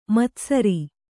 ♪ matsari